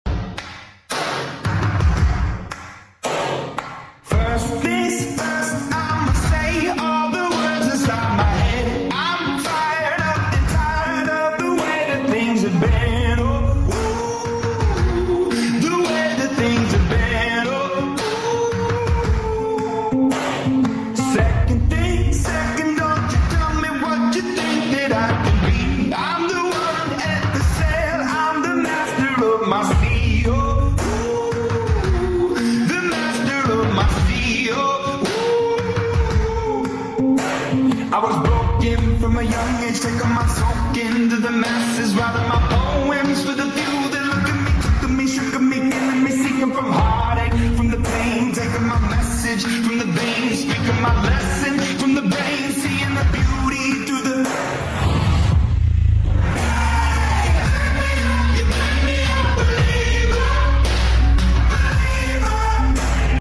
This 3d audio effekt 🤩🤩🤩 sound effects free download